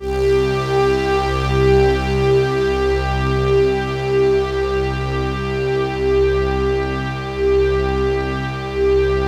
PAD3  C2  -L.wav